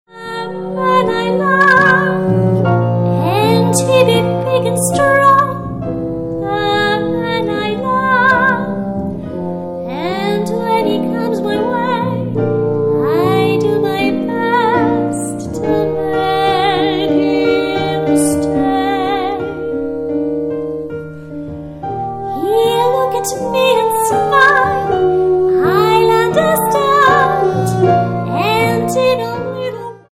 Ufa-Schlager und Jazziges